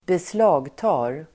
Uttal: [²besl'a:gta:r]